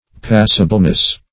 Passibleness \Pas"si*ble*ness\, n.